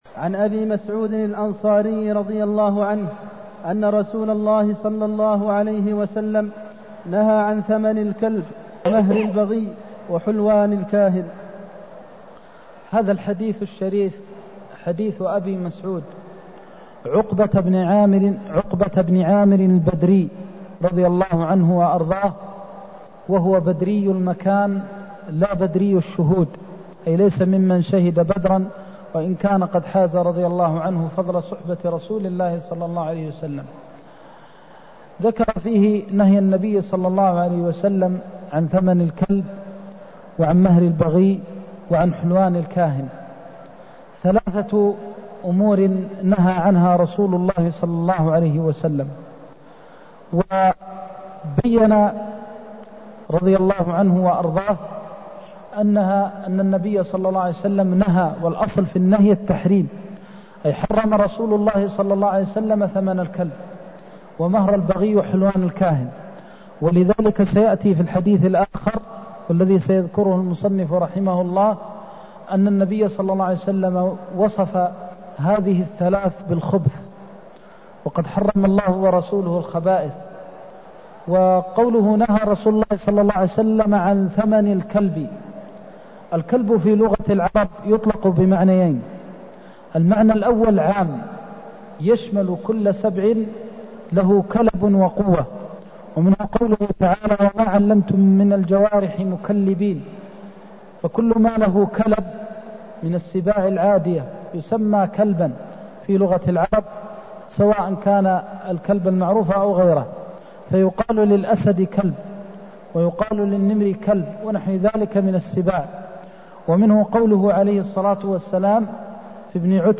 المكان: المسجد النبوي الشيخ: فضيلة الشيخ د. محمد بن محمد المختار فضيلة الشيخ د. محمد بن محمد المختار نهيه عن ثمن الكلب ومهر البغي وحلوان الكاهن (252)